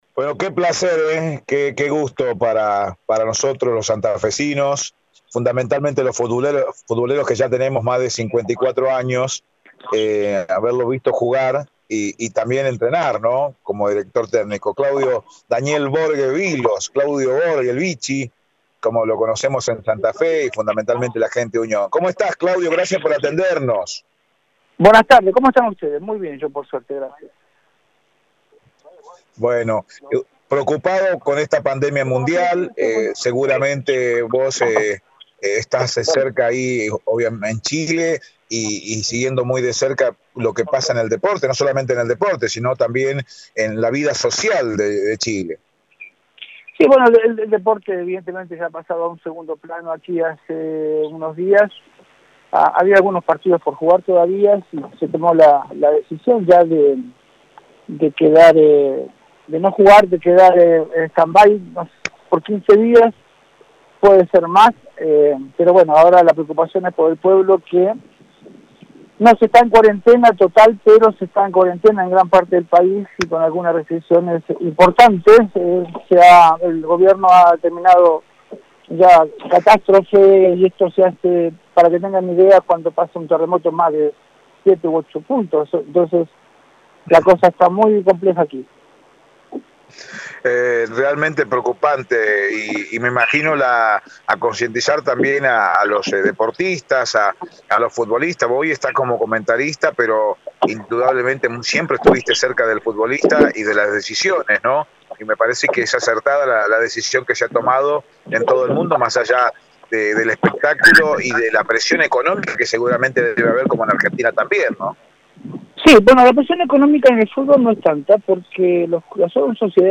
En Radio Eme Deportivo dialogamos en exclusiva con Claudio Borghi, exfutbolista y director técnico argentino nacionalizado chileno.
CHARLA-DE-CAFÉ-CON-CLAUDIO-BORGHI.mp3